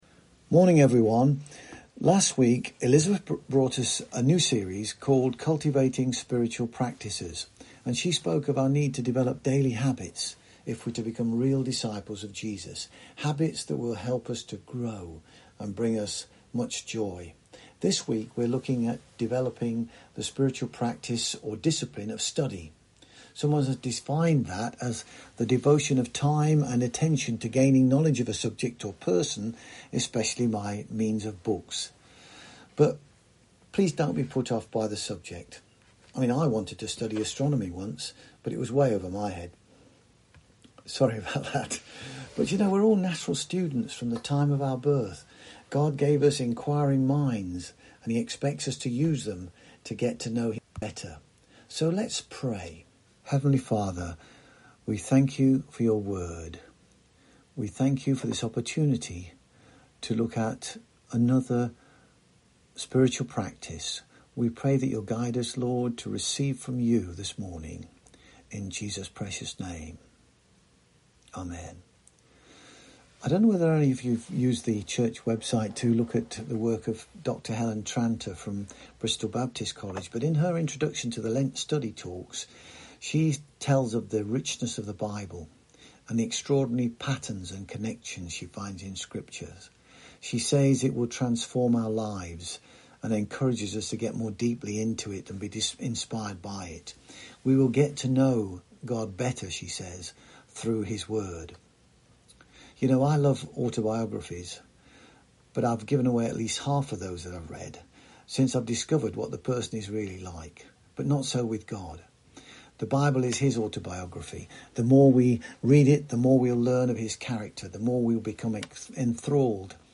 Series: Cultivating Spiritual Practices Service Type: Sunday Morning